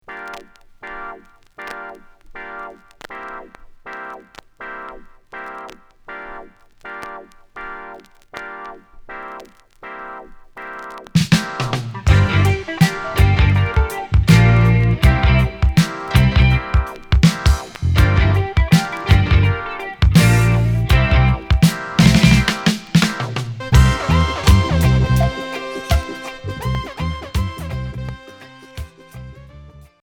Reggae funk